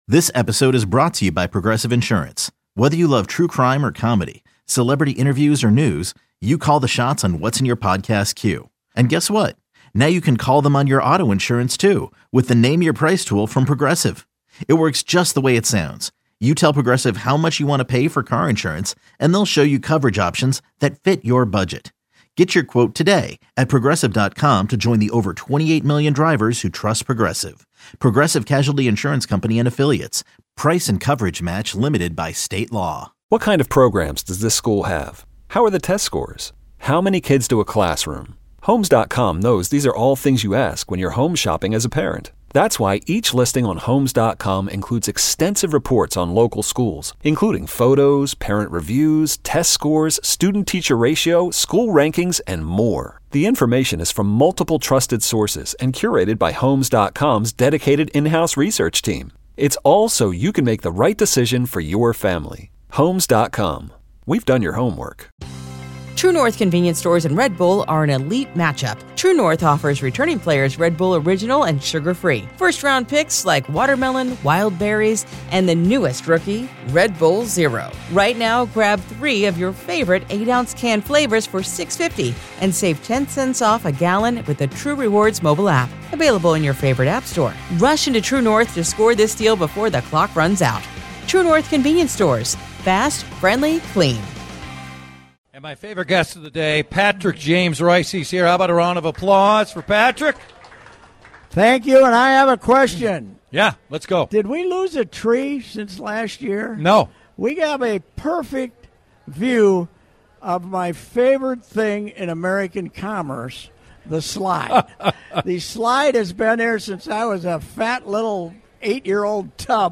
live at the Minnesota State Fair. They talk about the early days of the Target Center and Timberwolves basketball.